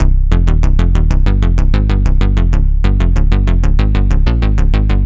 AM_OB-Bass_95-C.wav